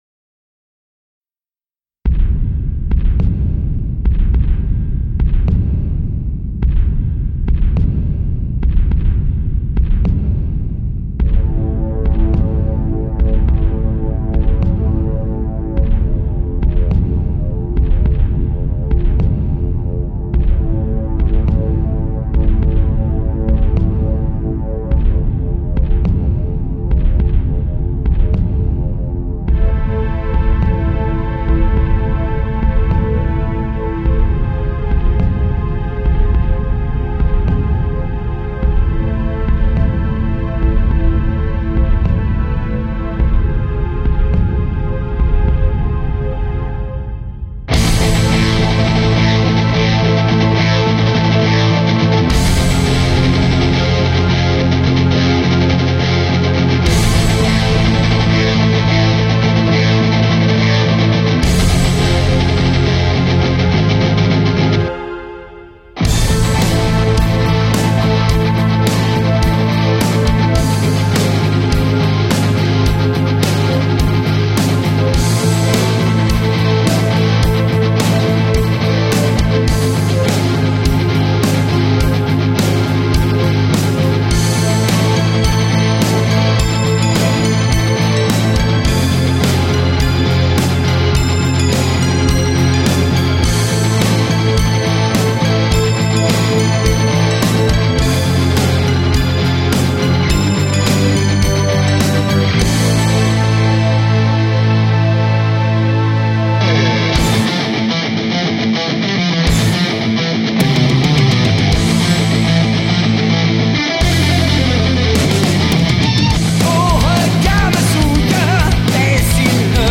heavy metal France